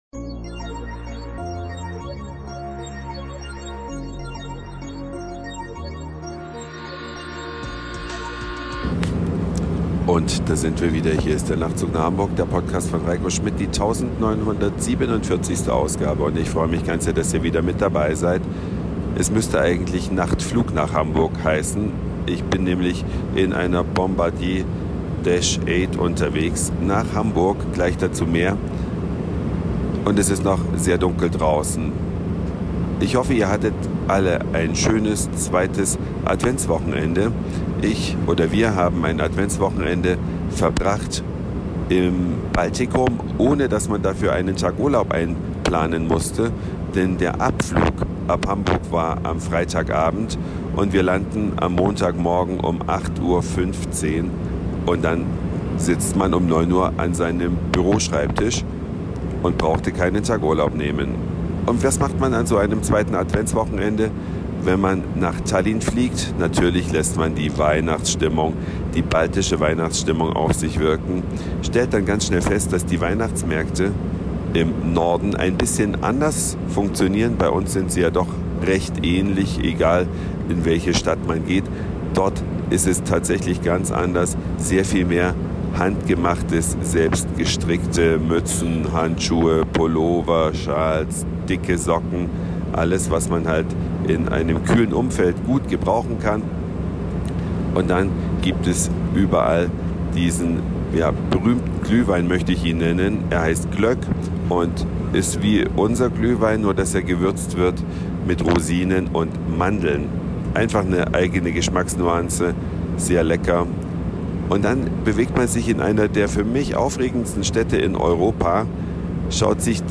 Eine Reise durch die Vielfalt aus Satire, Informationen, Soundseeing und Audioblog.
Das seltene „Geläut“ der Felsenkirche Weihnachtsmärkte im Norden